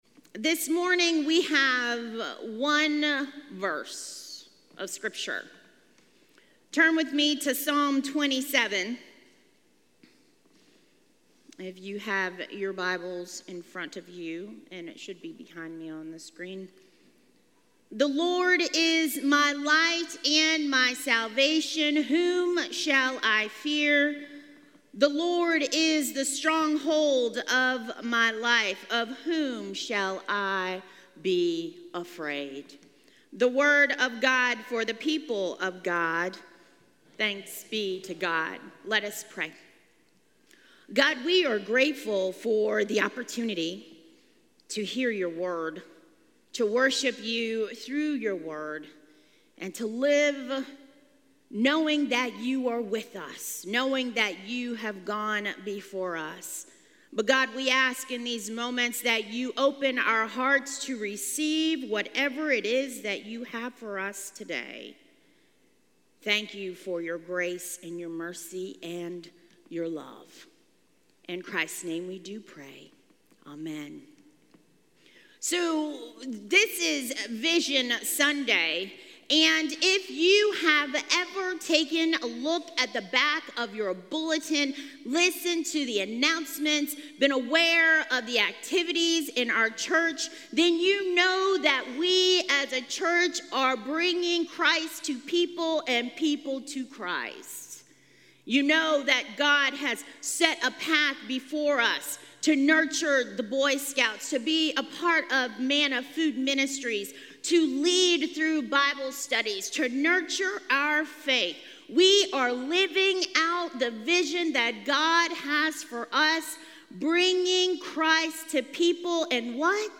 A message from the series "New Year, Same Promises."